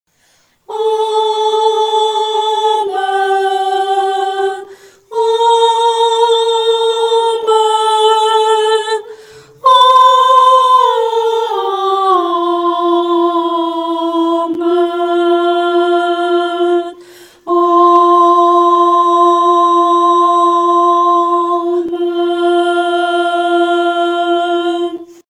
女高
本首圣诗由网上圣诗班录制